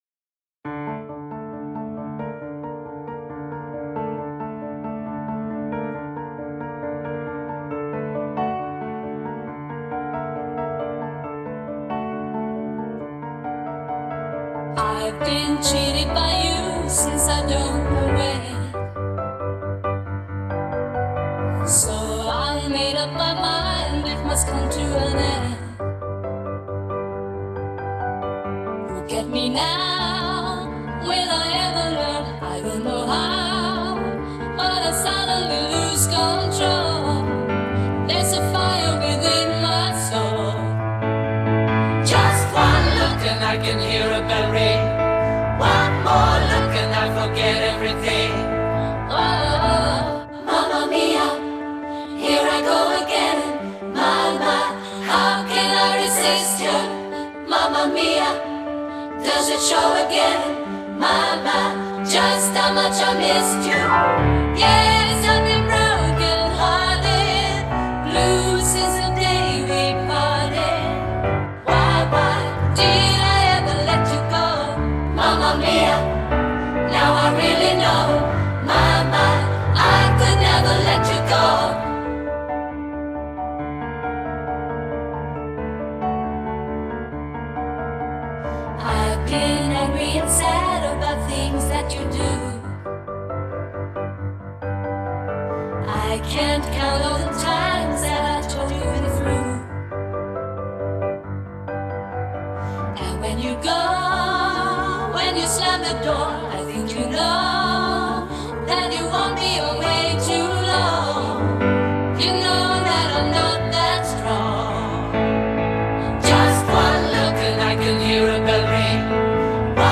What once felt playful now feels intimate.